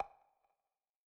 NEPTUNES PERC.wav